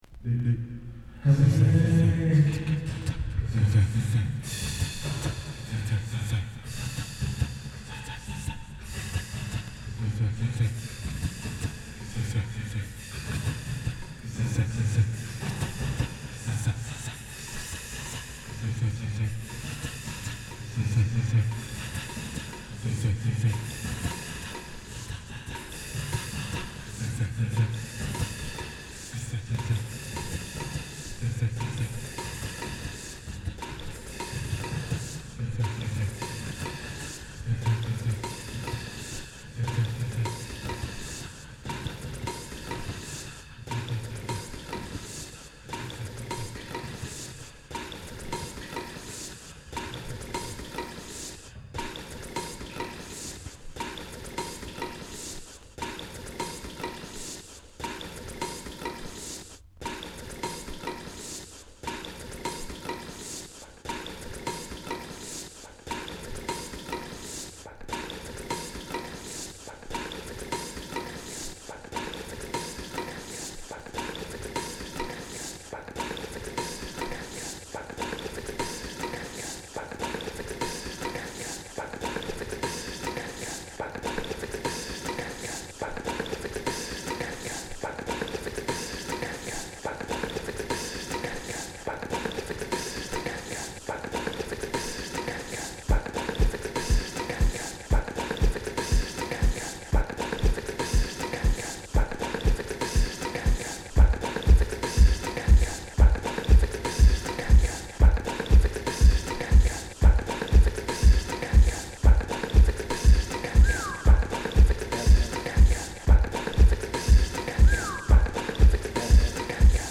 Detroit House